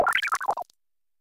Cri de Léboulérou dans Pokémon Écarlate et Violet.